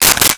Stapler 04
Stapler 04.wav